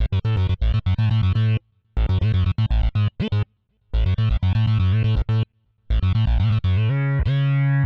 AmajSynthBass.wav